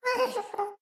sounds / mob / ghast / moan7.ogg
moan7.ogg